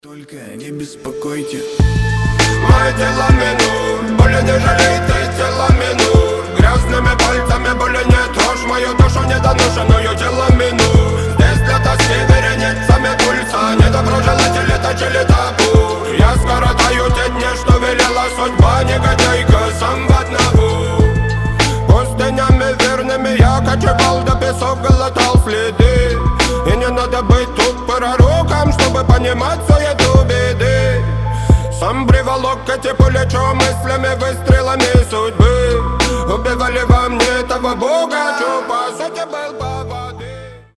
Рэп рингтоны
Грустные , Пианино